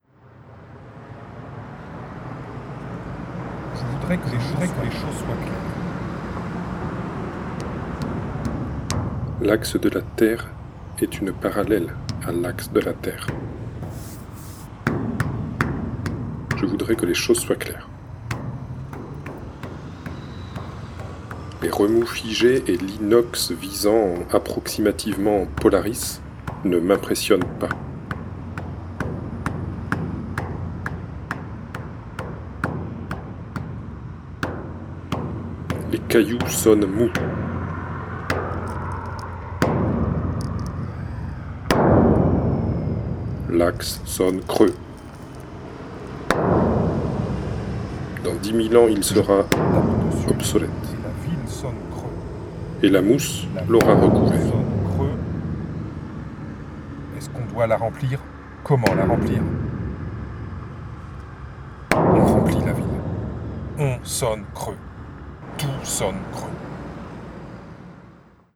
Son d’axe